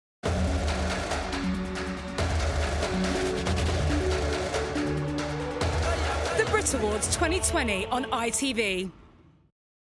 Neutral London.
• Female
• London